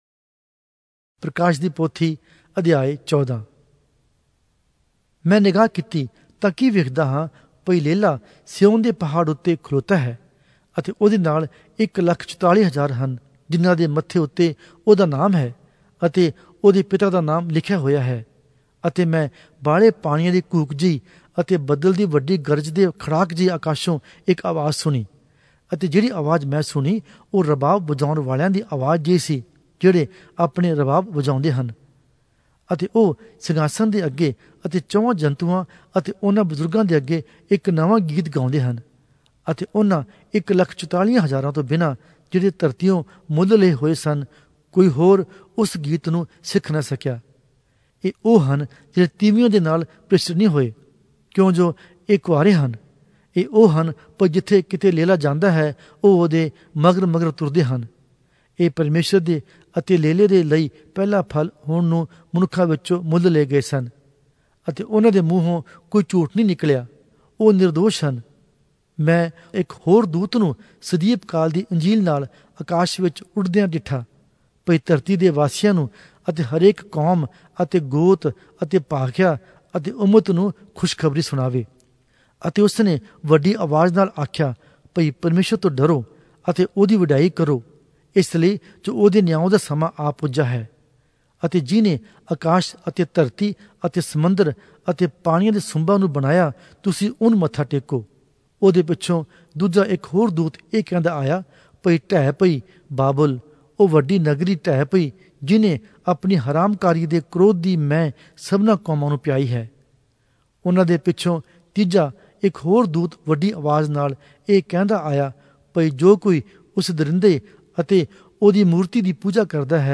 Oriya Audio Bible - Revelation 6 in Alep bible version